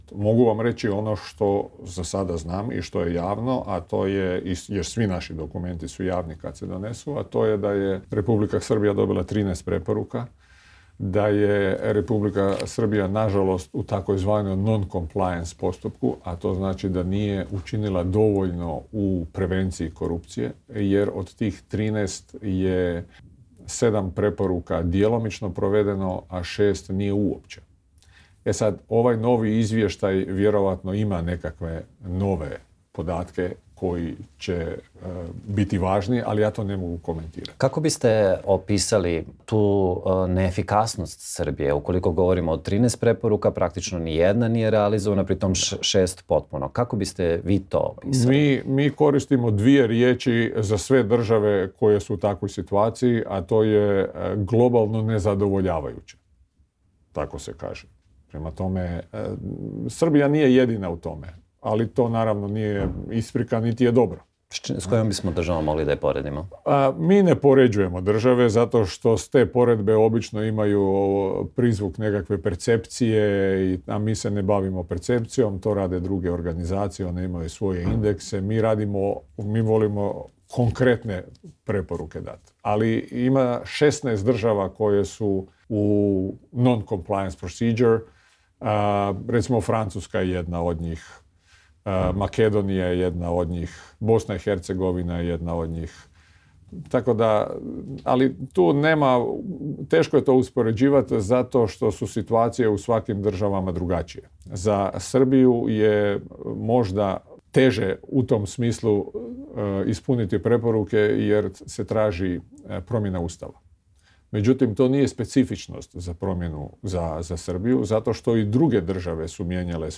Za "Intervju nedelje" RSE govori predsednik grupe GRECO i sudija Vrhovnog suda Hrvatske Marin Mrčela.
Intervju: Marin Mrčela